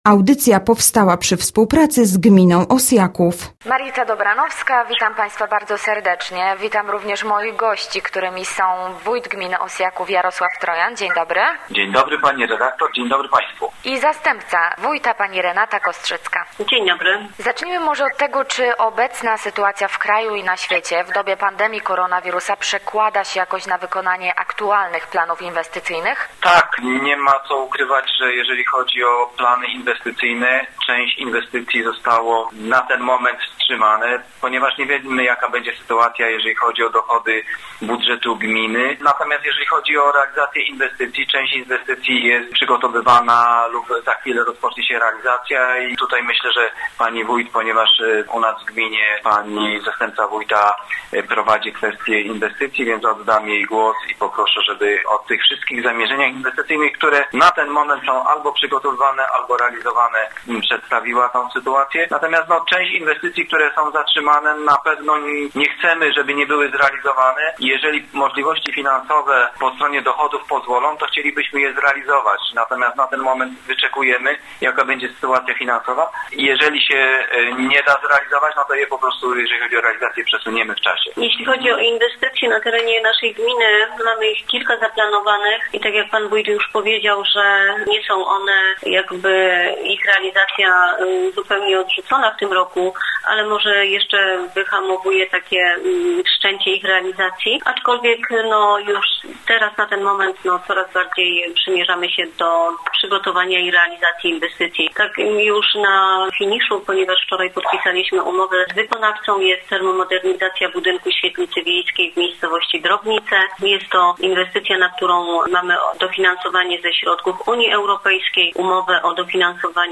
Gośćmi Radia ZW byli wójt gminy Osjaków, Jarosław Trojan oraz jego zastępca, Renata Kostrzycka